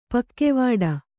♪ pakkevāḍa